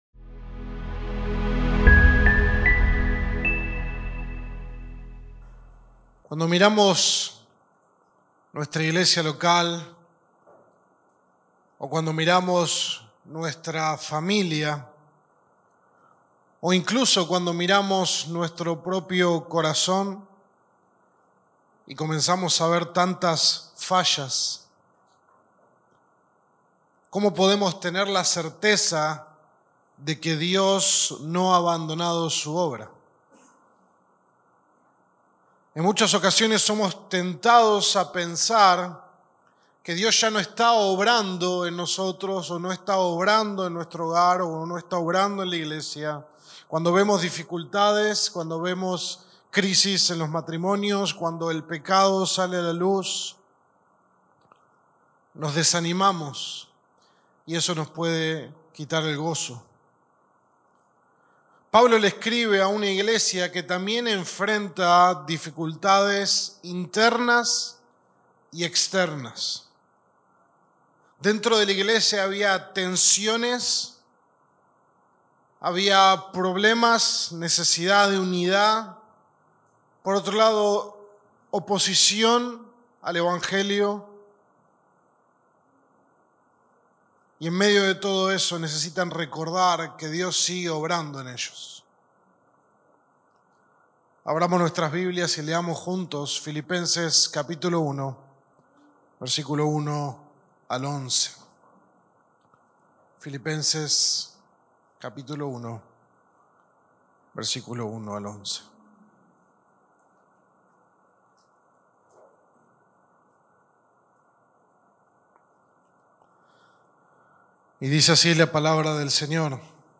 Sermón 1 de 8 en Cristo es Nuestra Vida